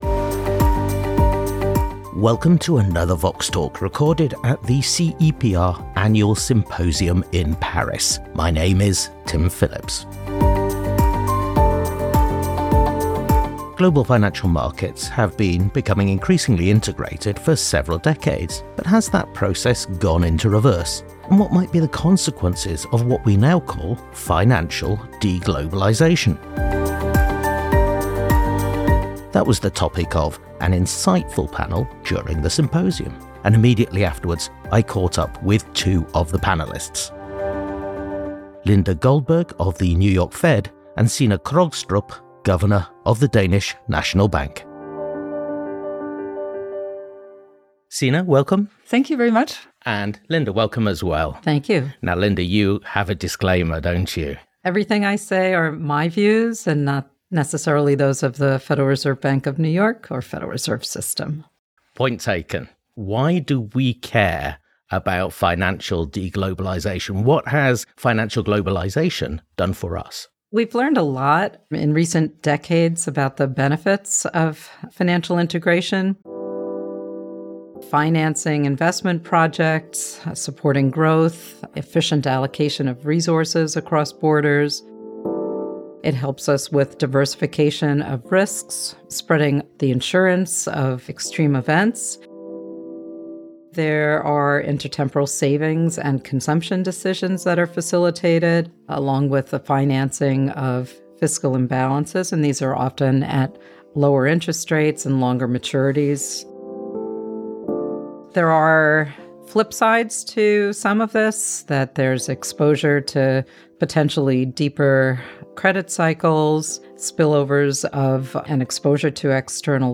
Recorded at the CEPR Paris Symposium.